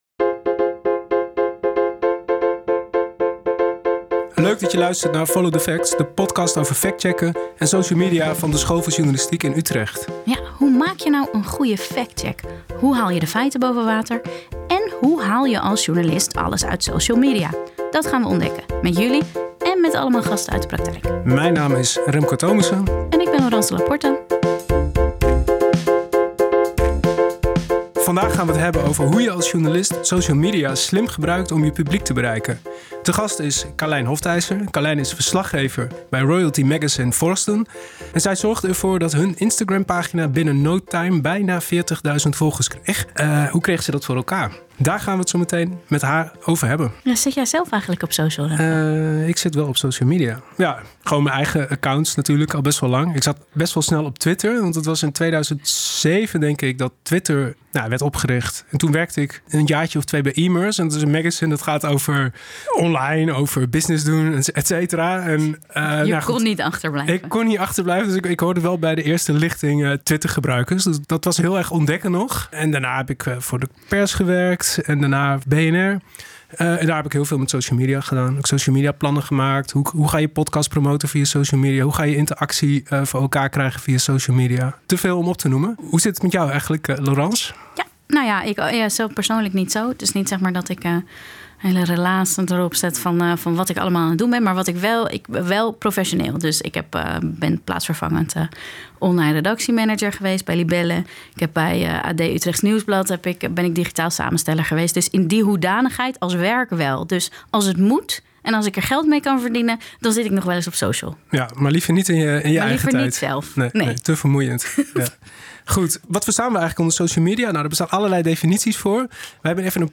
Jingles intro en outro